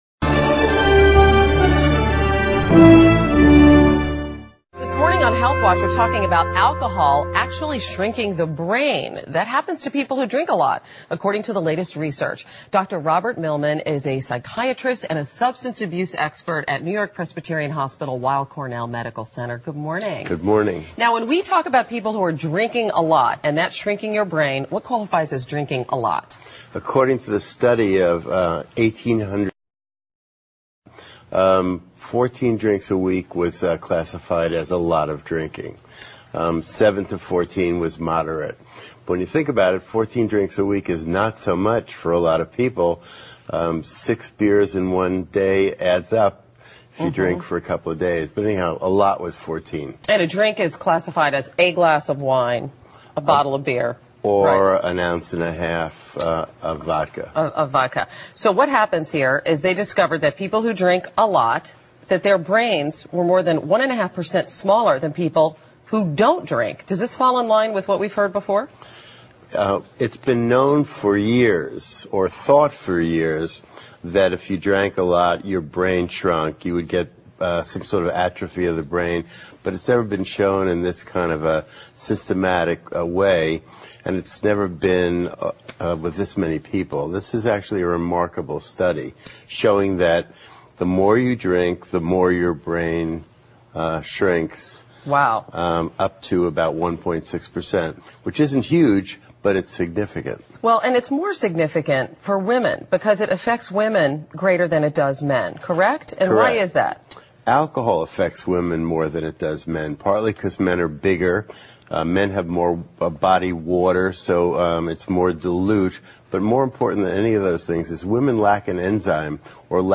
访谈录 Interview 2007-05-09&11, 喝酒会导致大脑变小？